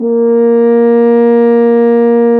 BRS F HRN 0D.wav